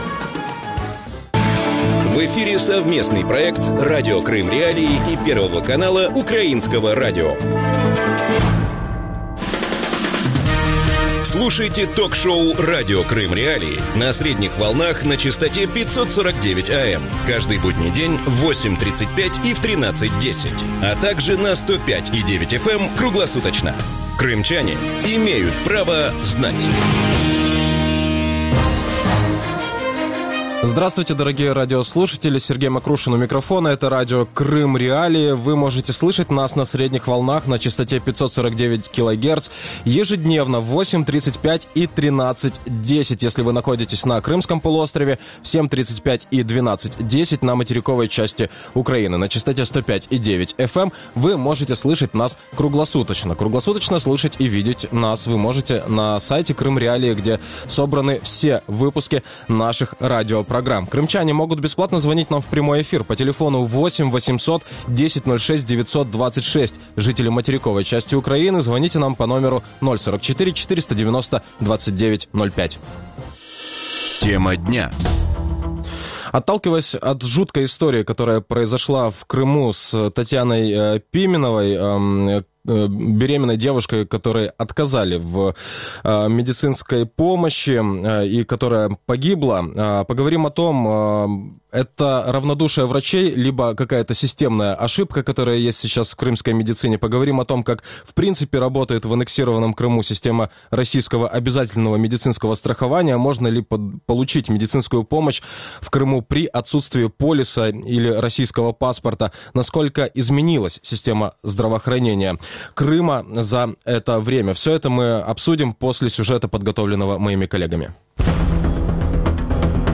Насколько изменилась система здравоохранения Крыма после аннексии? Гости эфира: